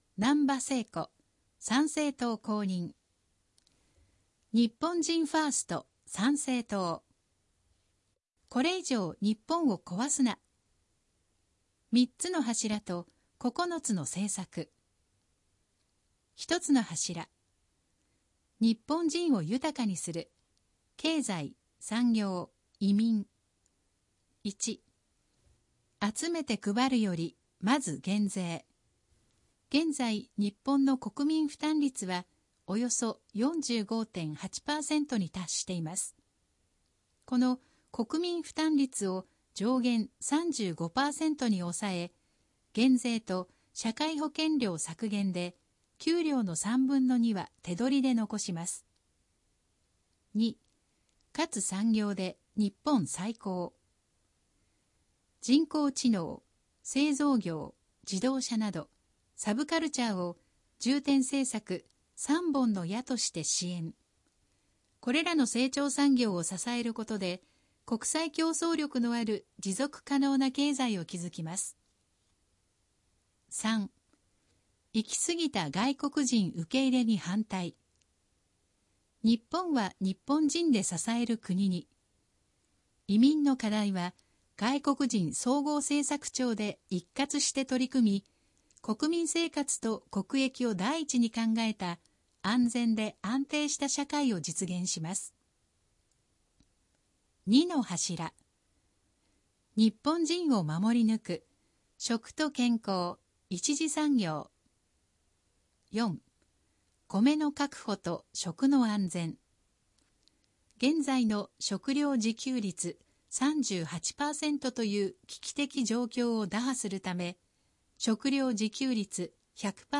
（選挙公報の音声読み上げ対応データは、現時点で提出のあった候補者のみ掲載しています）